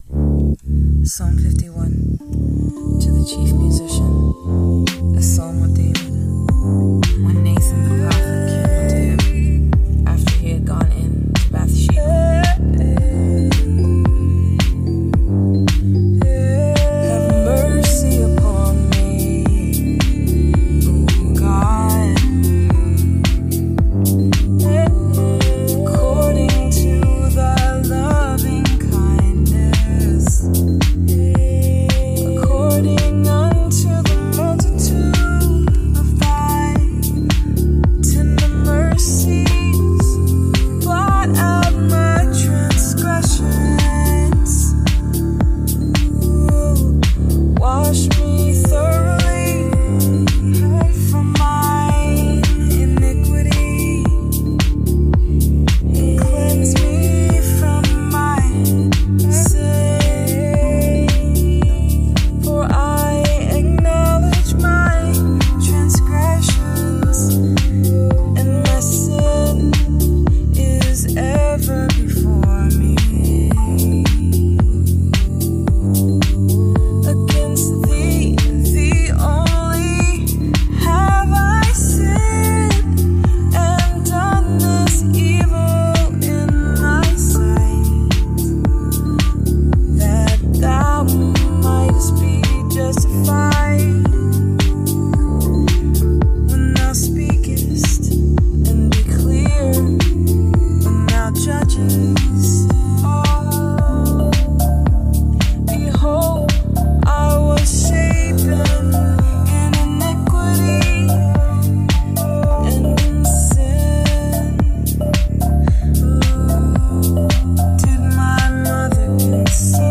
Psalm 51 Rav Vast & Beats Sessions 11-8-24